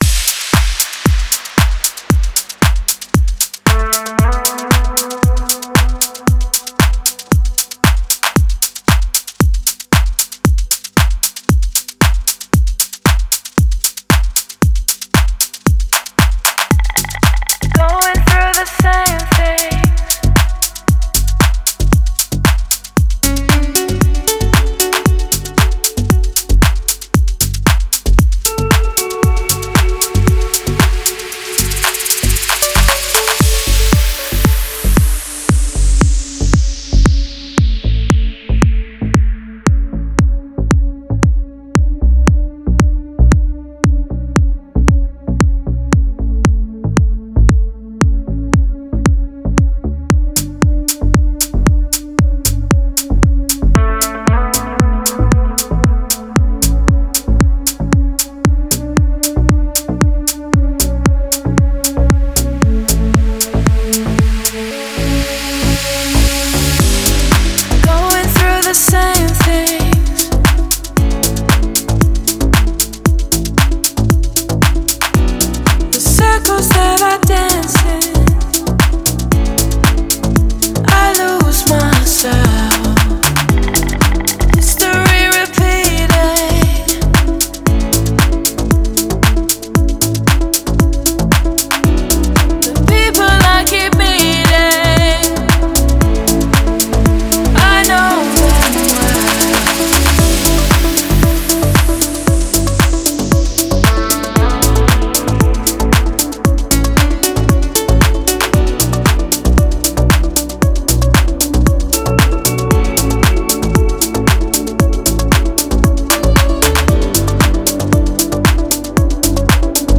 Deep House музыка
дип хаус песни